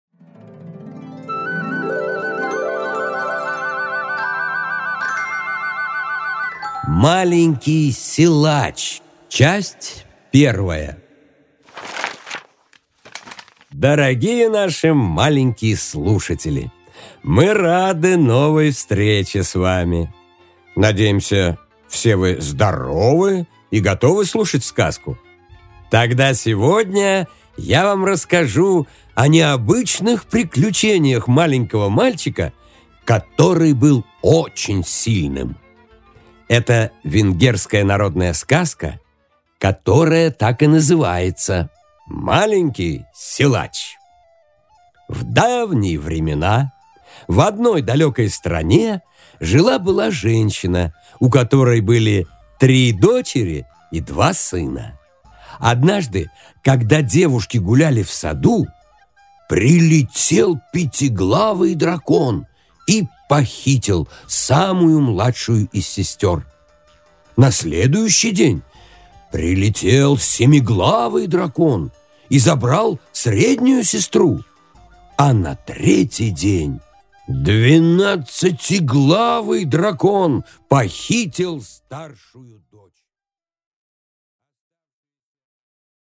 Аудиокнига Маленький силач